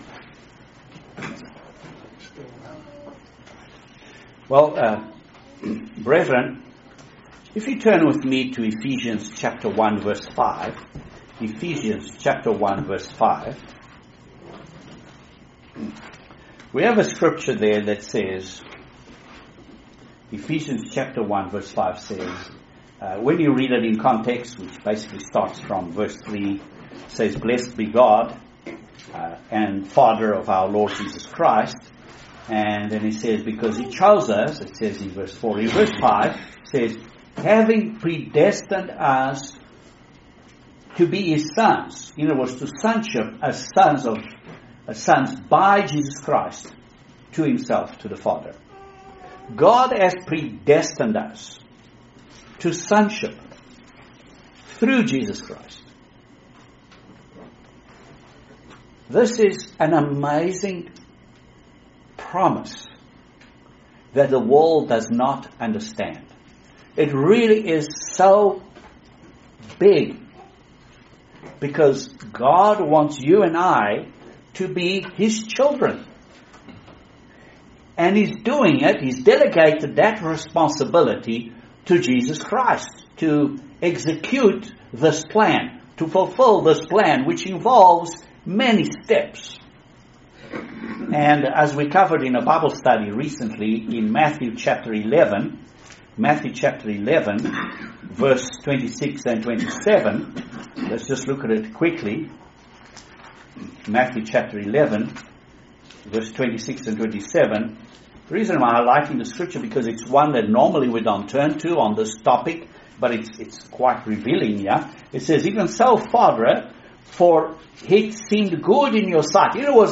video sermon